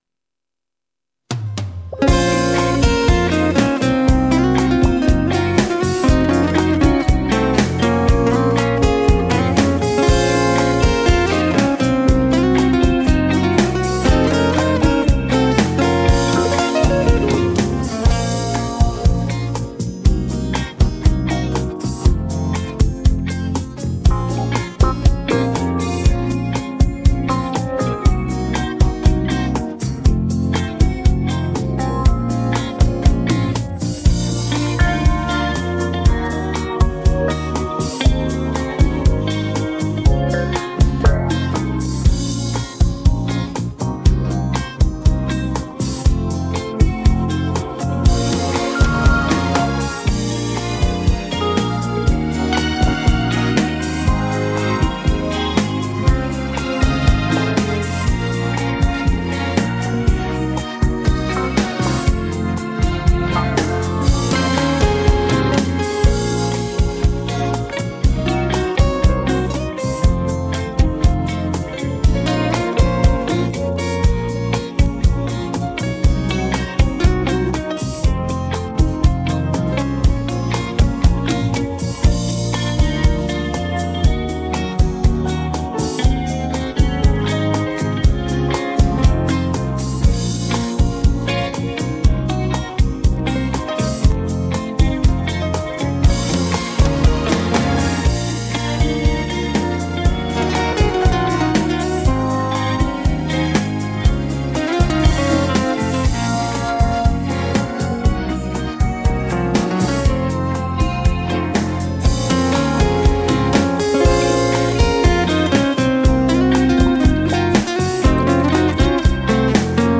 Слушать минус